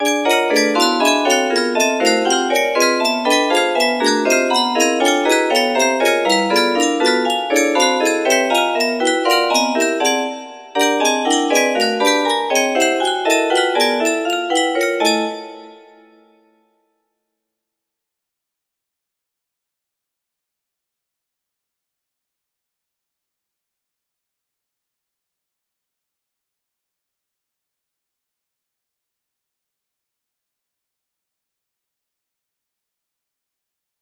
P23 music box melody